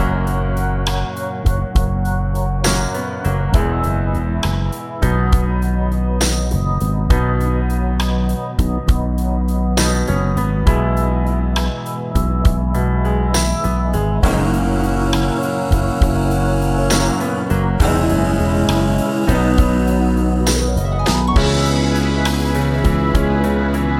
Two Semitones Down Pop (1990s) 3:55 Buy £1.50